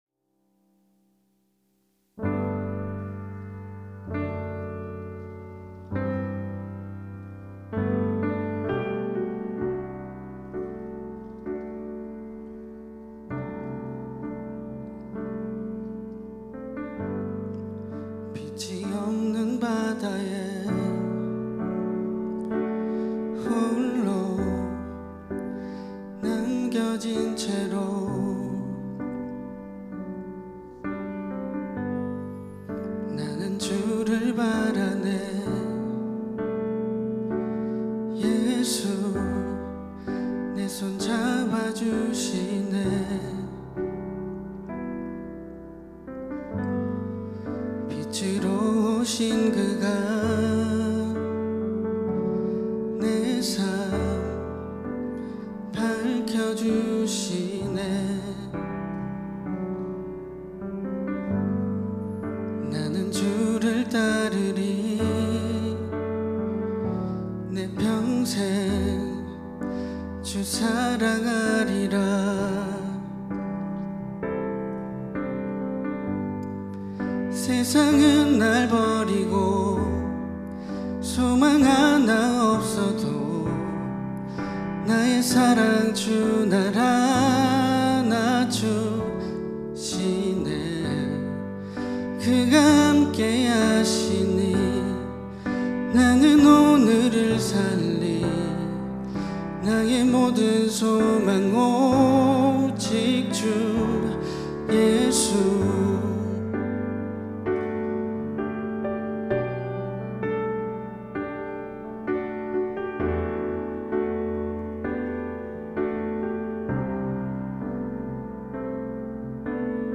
특송과 특주 - 나는 오늘을 살리